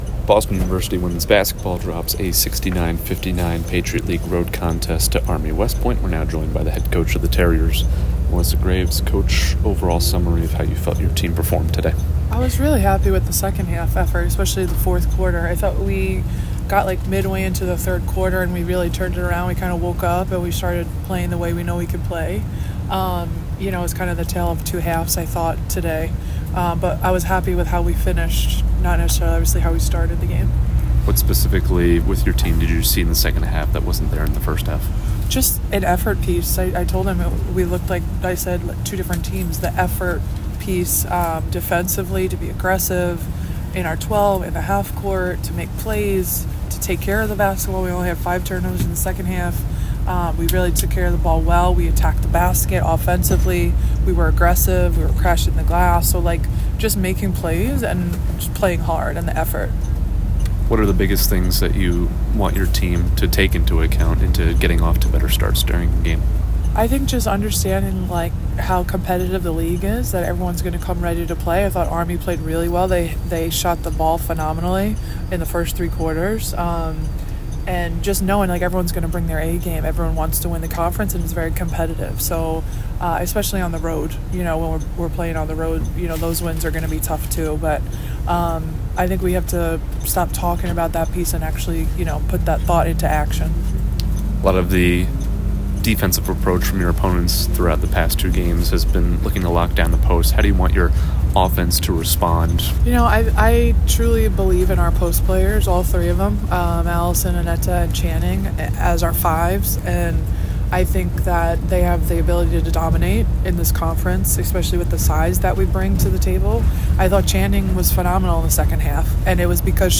Army West Point Postgame Interview